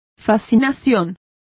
Complete with pronunciation of the translation of fascination.